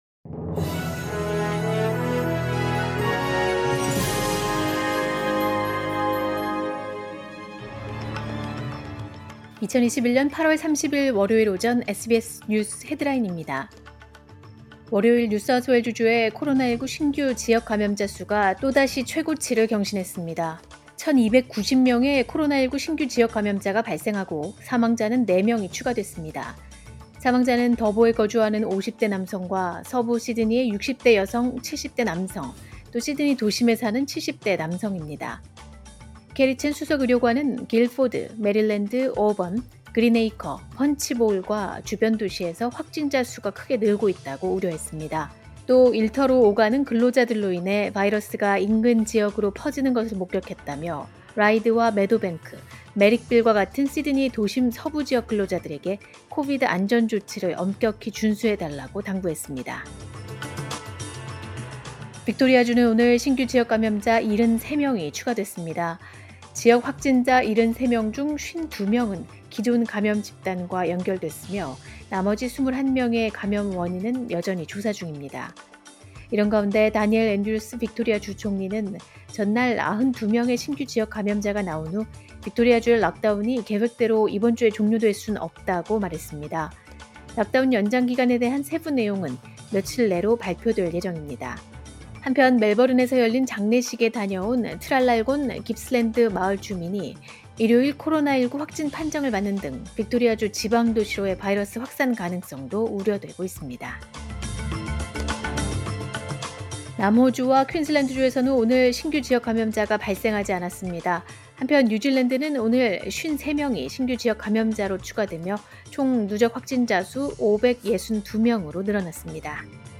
2021년 8월 30일 월요일 오전의 SBS 뉴스 헤드라인입니다.